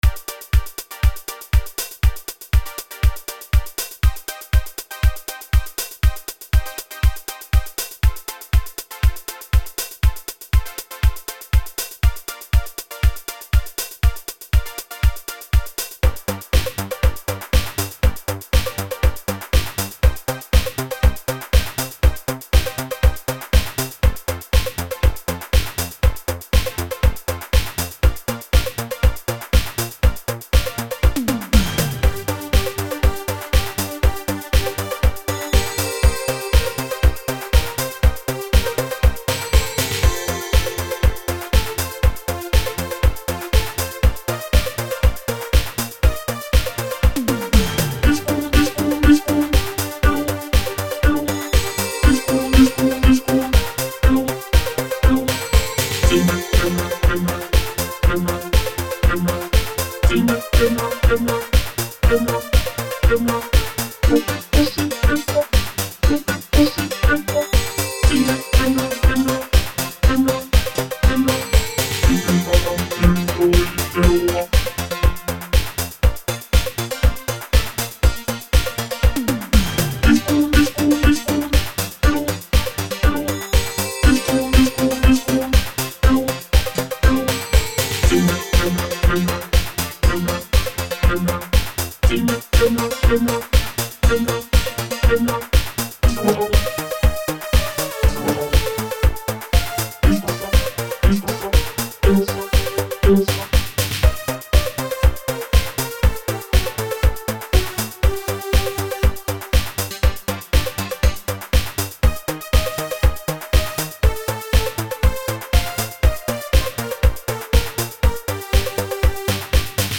80-luvun italodiskotyylisen coverin